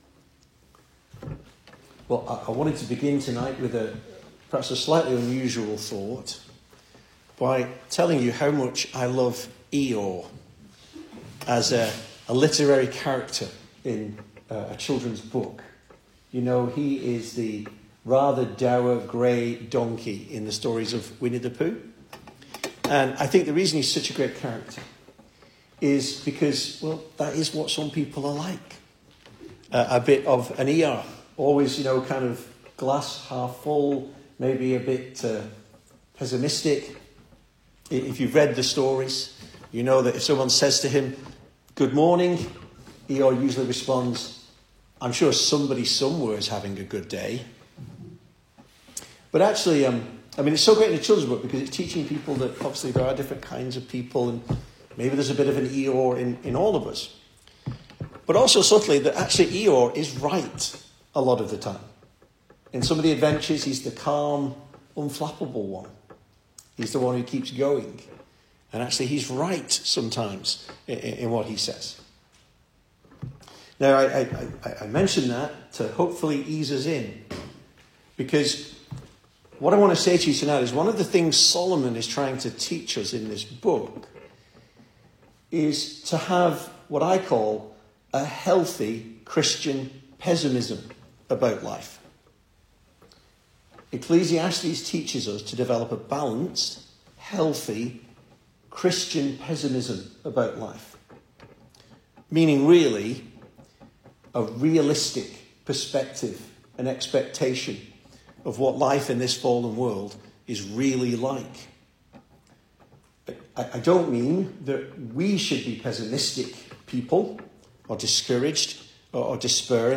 2025 Service Type: Weekday Evening Speaker